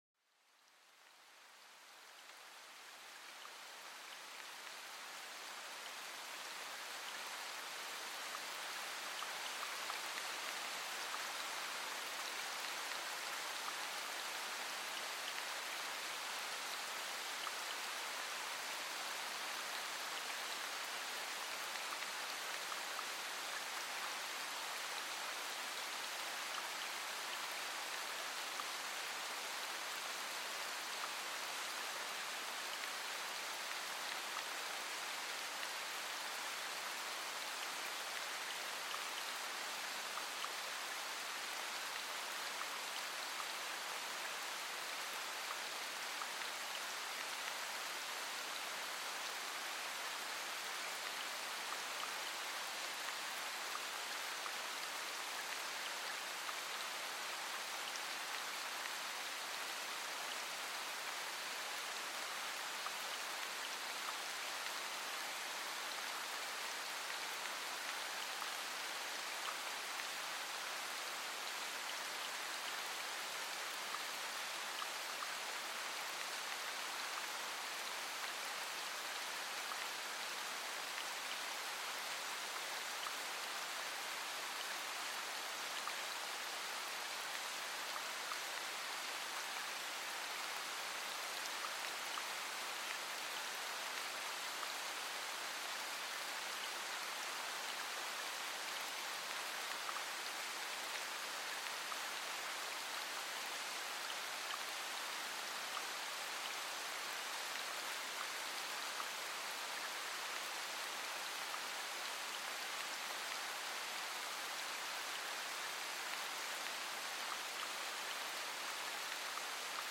Découvrez le son apaisant de la pluie, une présence douce qui purifie l'air et rafraîchit la terre, cet épisode capture l'essence rafraîchissante de la pluie, offrant une pause rajeunissante dans le tumulte quotidien, laissez-vous envelopper par le rythme tranquille de la pluie, évoquant un sentiment de sérénité et de renouveau.Ce podcast est une expérience audio immersive qui plonge les auditeurs dans les merveilleux sons de la nature.